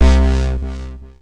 low_fade.wav